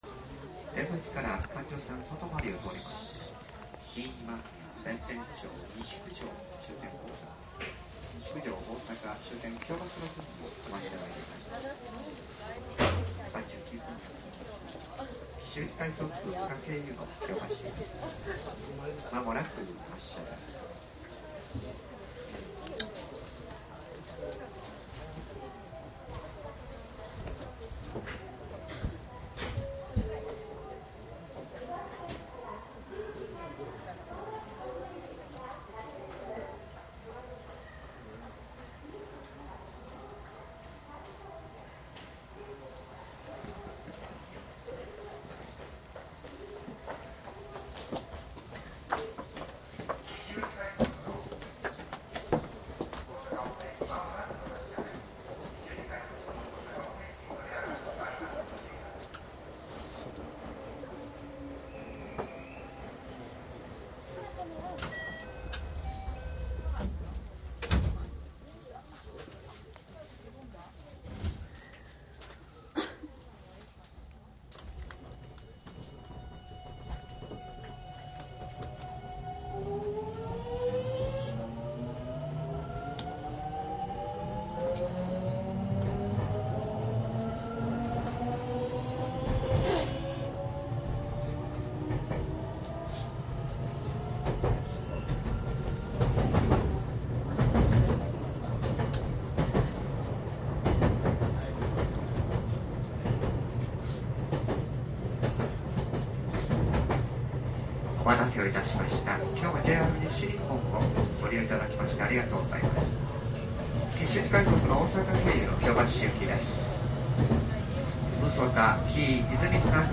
商品説明  ♪JR阪和線 鉄道走行音 ＣＤ ♪
かなり以前に録音した 関空・紀州路快速の録音です。
■【紀州路快速】 和歌山→日根野 クモハ223－104
マスター音源はデジタル44.1kHz16ビット（マイクＥＣＭ959）で、これを編集ソフトでＣＤに焼いたものです。
貸切ではないので乗客の会話やが全くないわけではありません。